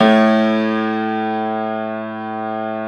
53d-pno05-A0.wav